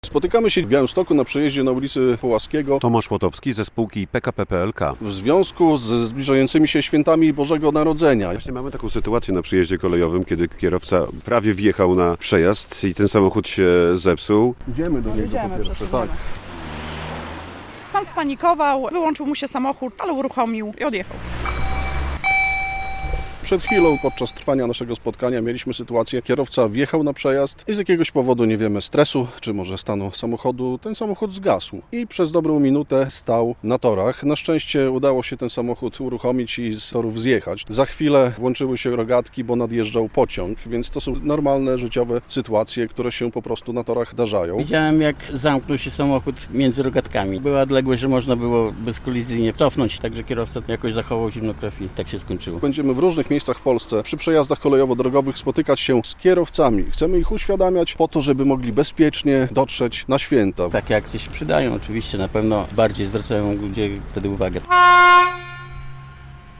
Akcja "Bezpieczny przejazd" - relacja